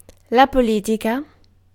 Ääntäminen
IPA : /pə.ˈlɪt.ɪ.kəl/